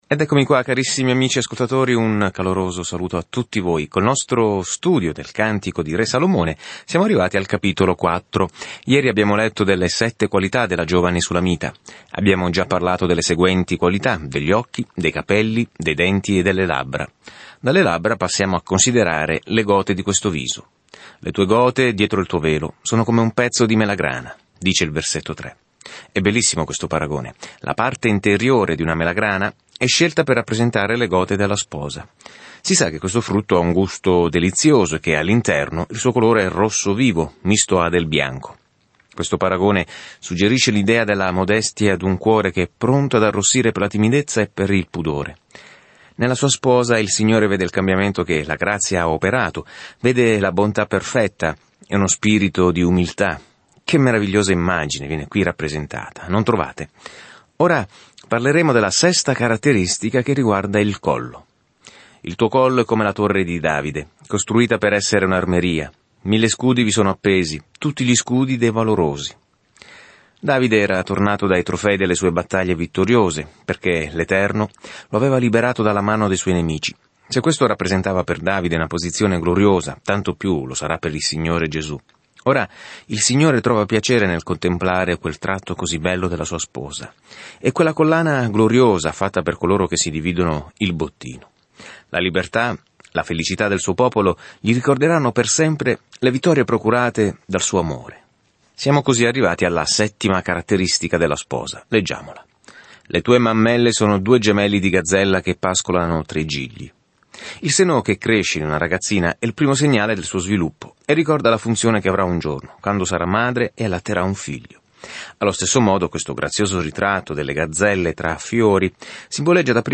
Scrittura Cantico dei Cantici 4:6-16 Cantico dei Cantici 5:1-5 Giorno 6 Inizia questo Piano Giorno 8 Riguardo questo Piano I Cantici dei Cantici è una piccola canzone d'amore che celebra l'amore, il desiderio e il matrimonio con un ampio paragone con il modo in cui Dio ci ha amati per la prima volta. Viaggia ogni giorno attraverso il Cantico dei Cantici mentre ascolti lo studio audio e leggi versetti selezionati della parola di Dio.